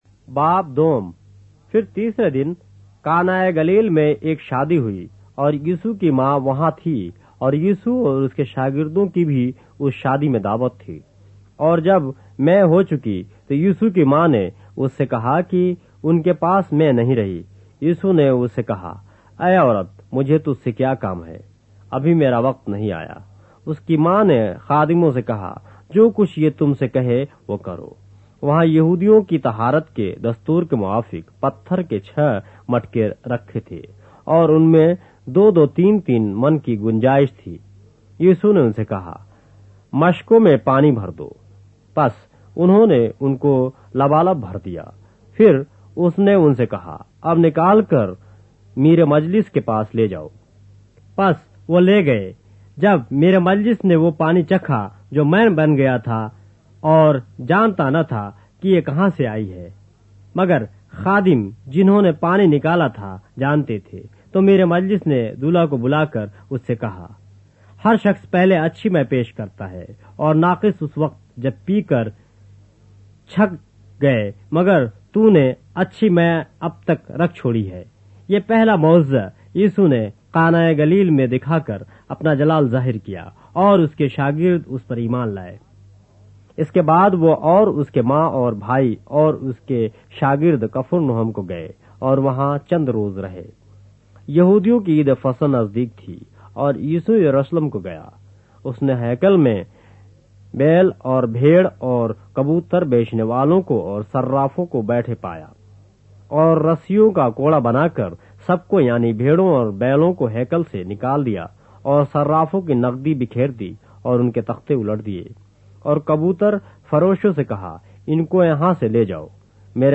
اردو بائبل کے باب - آڈیو روایت کے ساتھ - John, chapter 2 of the Holy Bible in Urdu